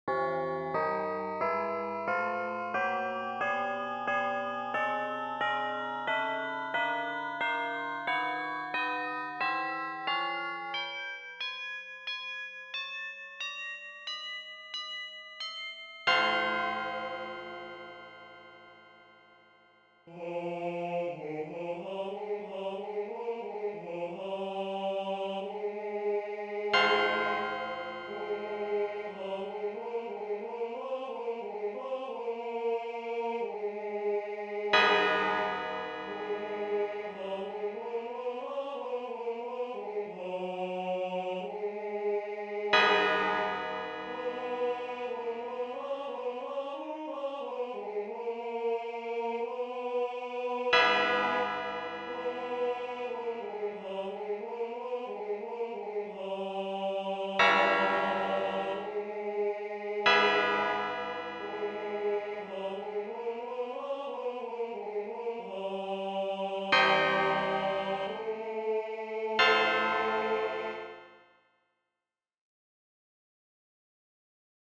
for Male Voices